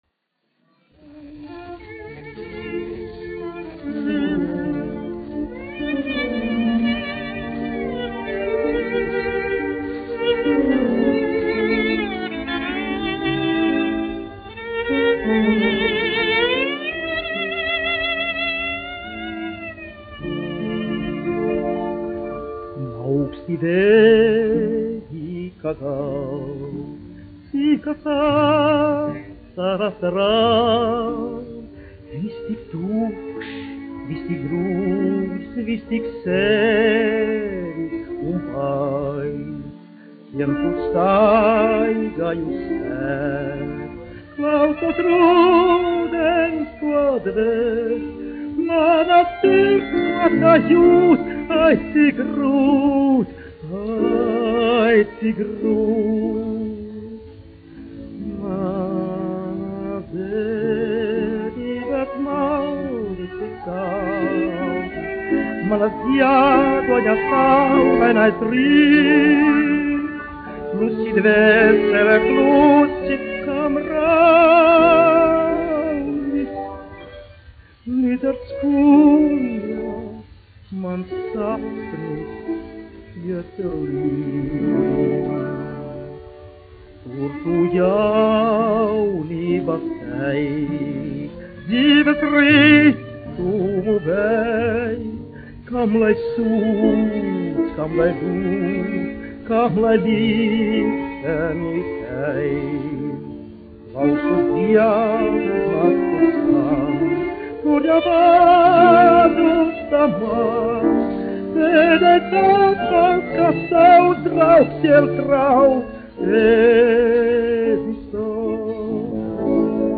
1 skpl. : analogs, 78 apgr/min, mono ; 25 cm
Romances (mūzika)
Dziesmas (augsta balss)
Skaņuplate